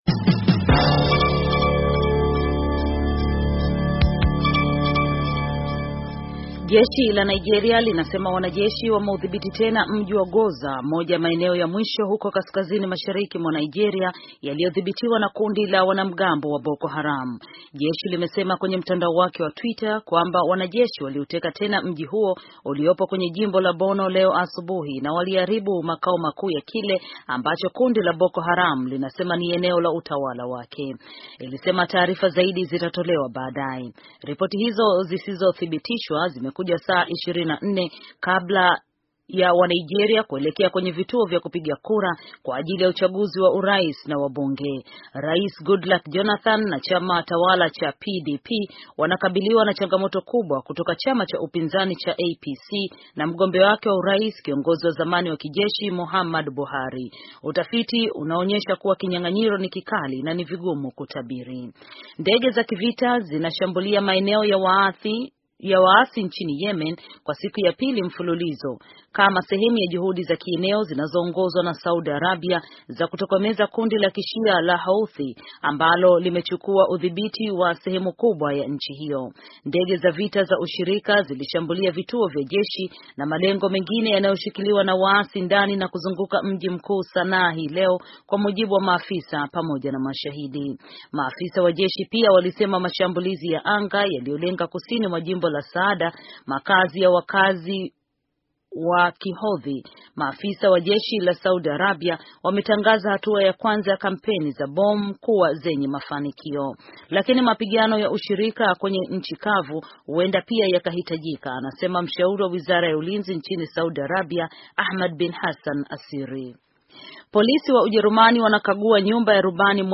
Taarifa ya habari - 4:31